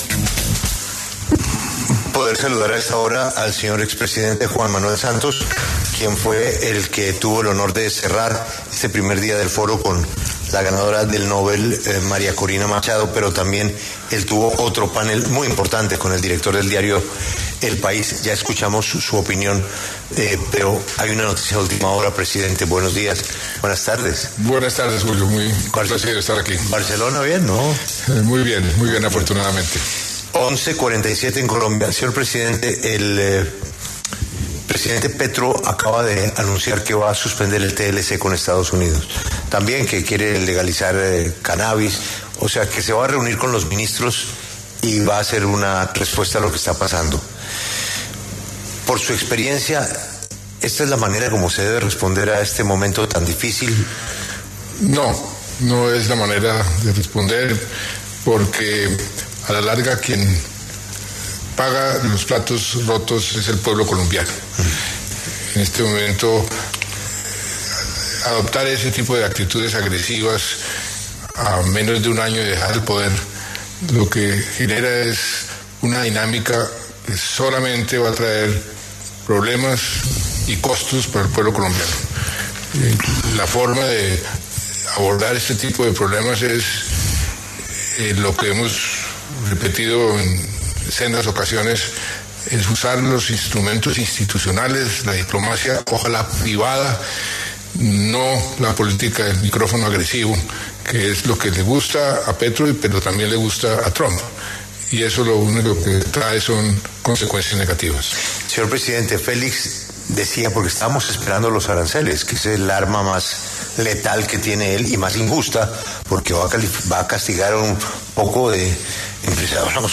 Juan Manuel Santos, expresidente, habla en La W sobre el choque entre Gustavo Petro y Donald Trump.